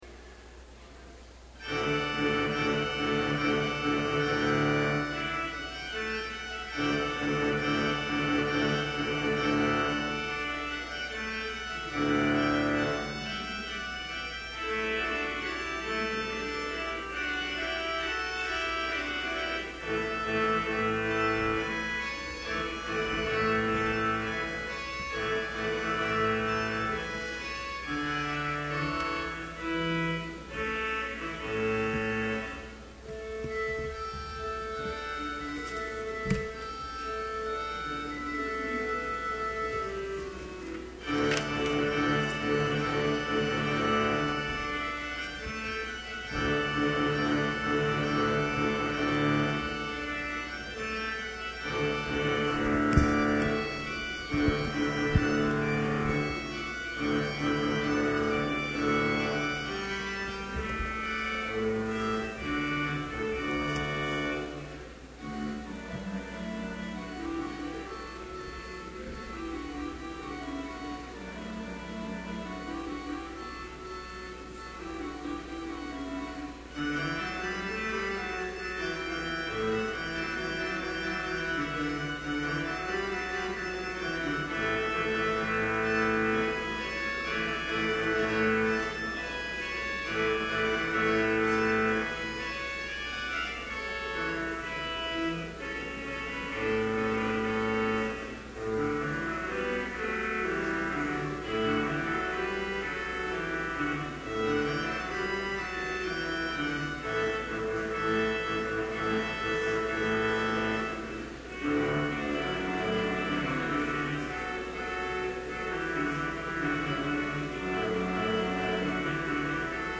Complete service audio for Chapel - November 1, 2011
Order of Service Prelude Hymn 554, vv. 1-5, For All the Saints Scripture Reading: Psalm 33:18-19 Homily Prayer Hymn 554, vv. 7 & 8, But, lo, there breaks... Benediction Postlude